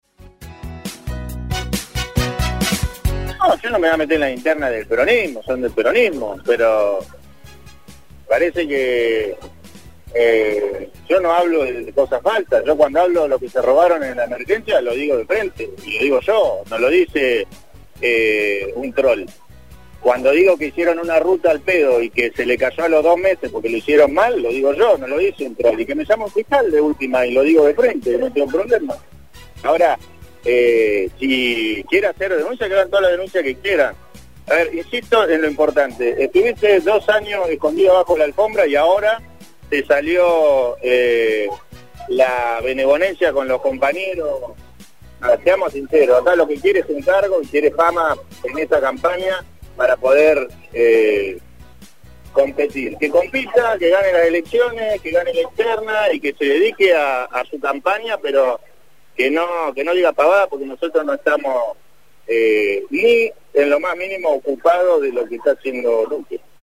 En la conferencia de prensa que brindó el gobernador Ignacio Torres luego del Acto por los 77 años de Rada Tilly, y ante la consulta de su opinión sobre la interna peronista, dijo que Luque sólo busca fama: